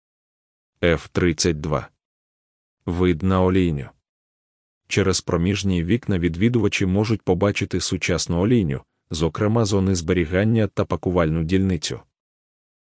Аудіогід